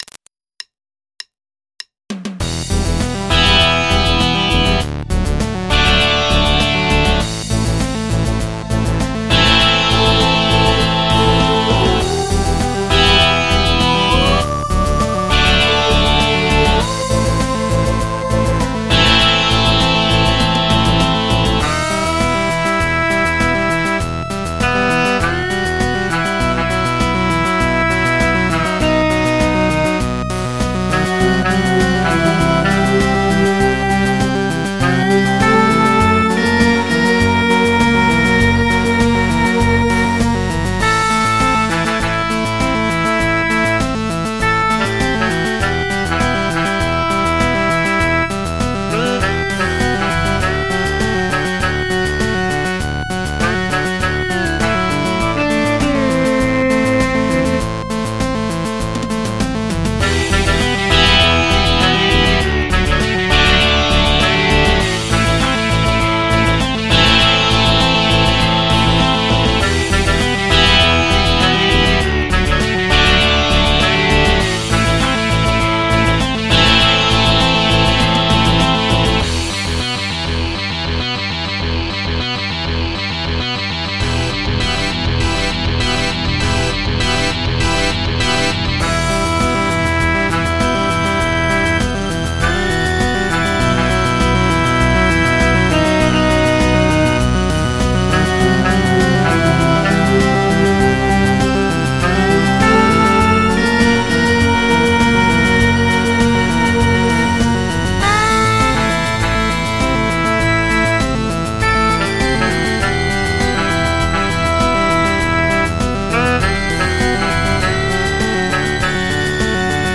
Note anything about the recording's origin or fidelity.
MP3 (Converted)